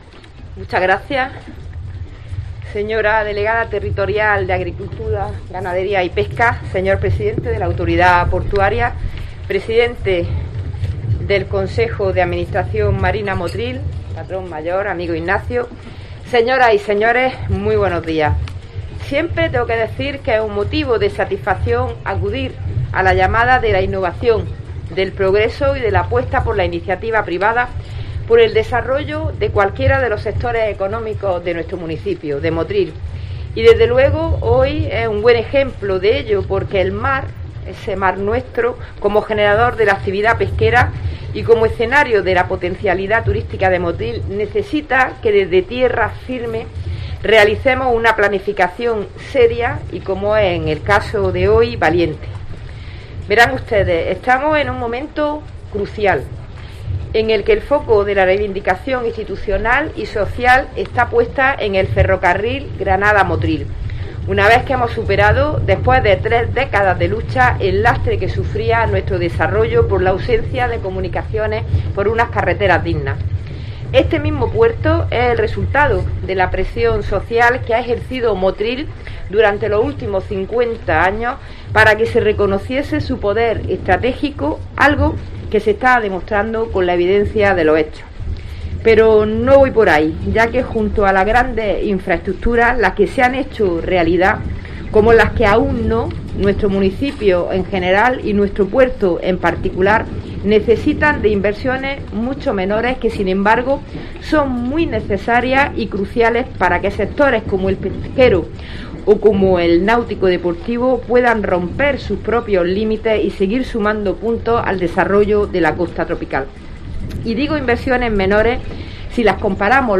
José García Fuentes, Pte. Autoridad Portuaria de Motril